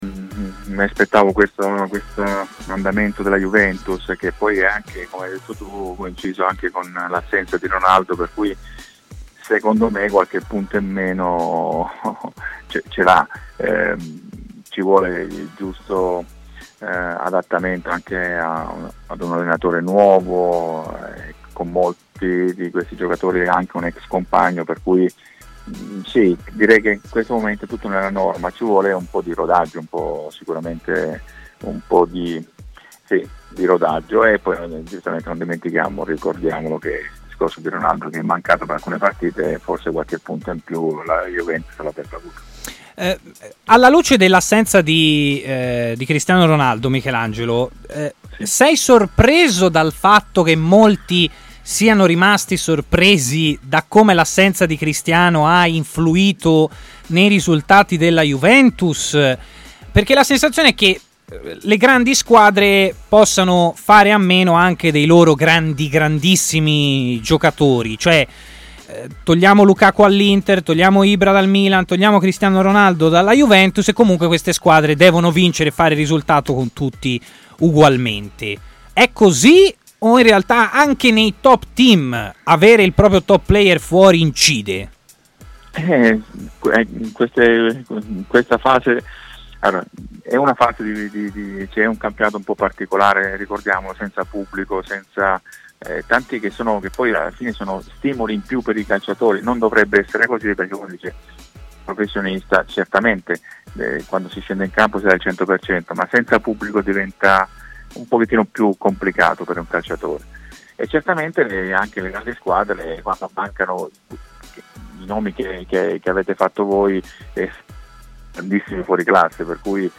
L'ex portiere Michelangelo Rampulla si è collegato in diretta con TMW Radio